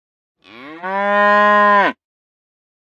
moo.ogg